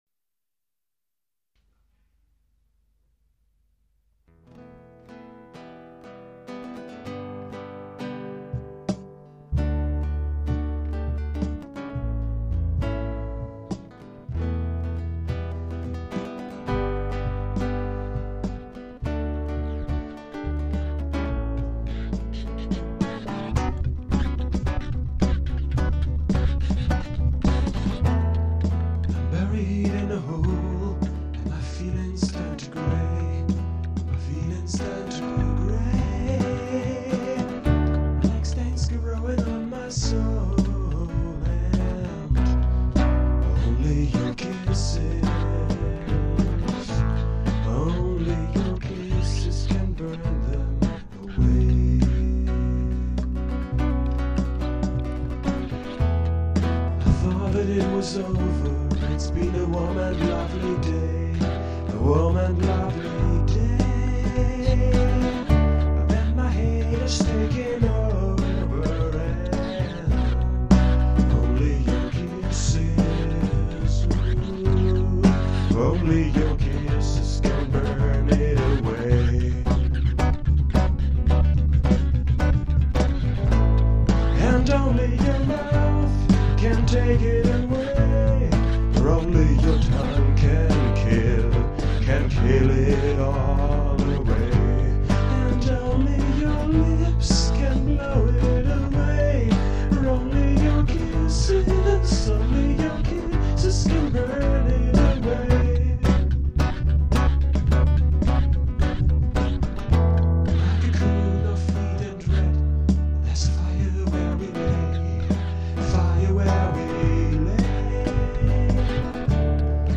vocals, lead & rhythm guitar, cajón and bass
lead guitar & electronica
additional percussion